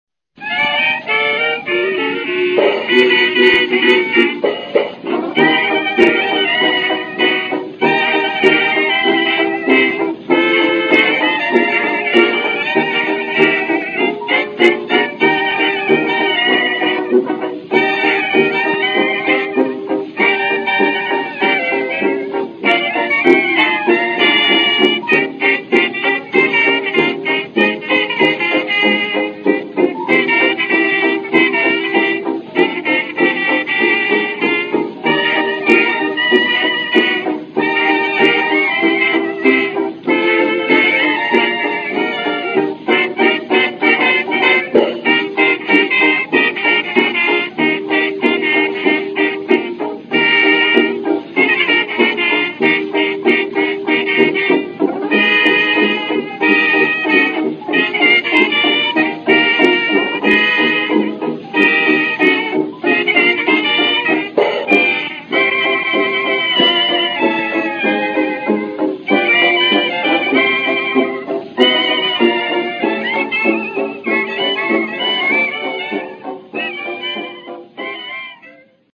New York, 14 novembre 1924